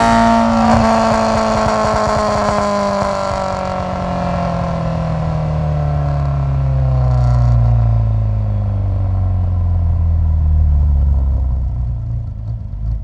rr3-assets/files/.depot/audio/Vehicles/4cyl_01/4cyl_01_decel.wav
4cyl_01_decel.wav